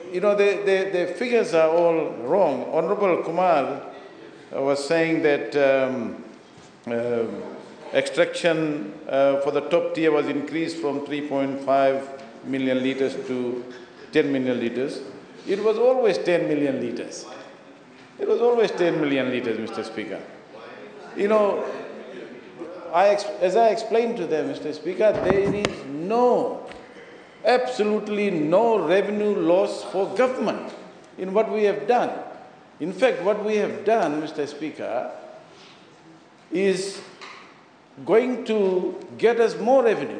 Parliament
Meanwhile, Finance Minister Professor Biman Prasad says they are increasing the water resource tax from 18 percent to 19.5 percent, which is actually going to increase the revenue that the government will get.